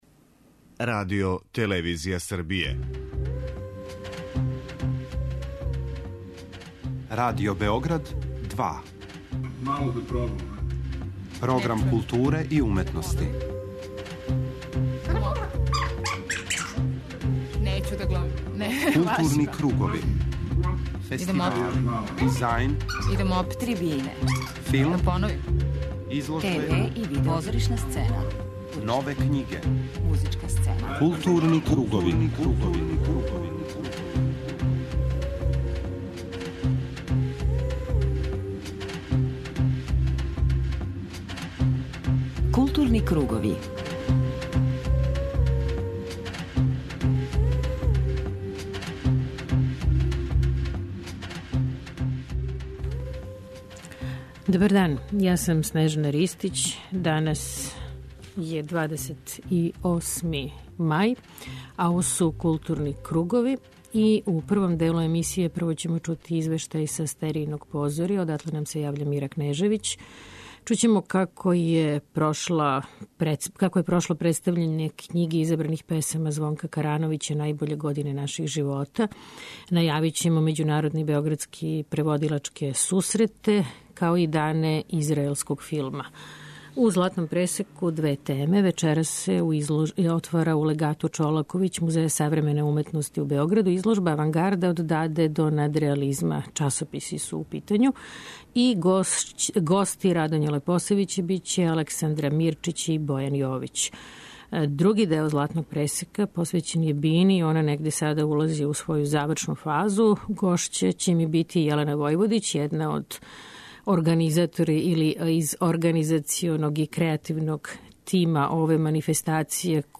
Чућете и снимке са неким од најзанимљивијих гостију.